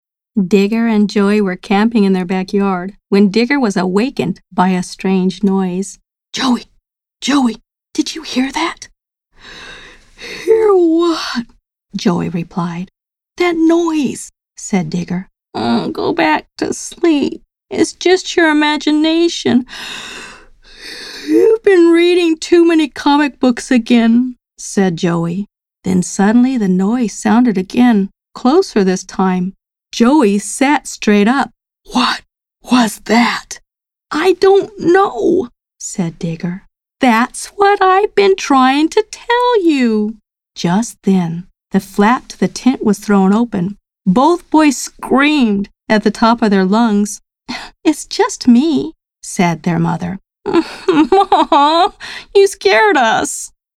My narration style is thoughtful and immersive, often described as grounding and familiar, helping listeners feel at home within the world of a story.
Children's Reading Demo
childrens-audiobook.mp3